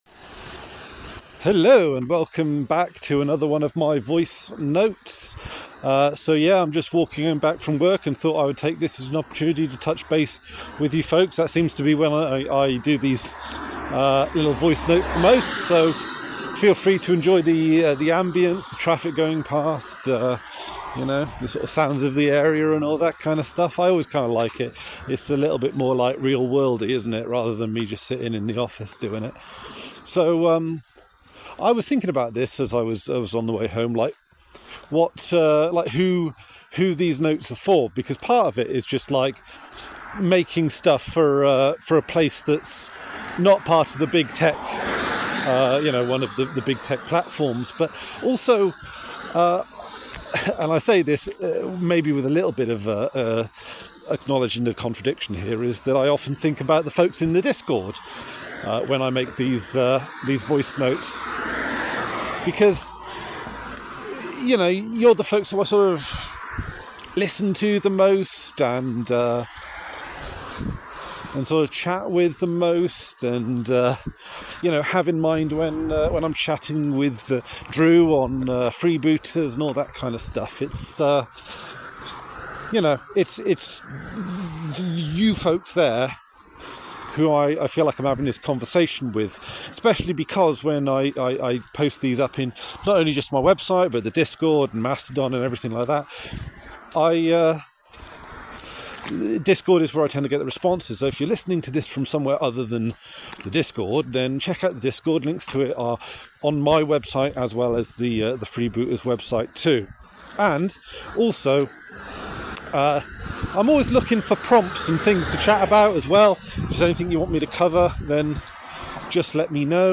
A voice note where I share my thoughts on AKotSK and Fallout.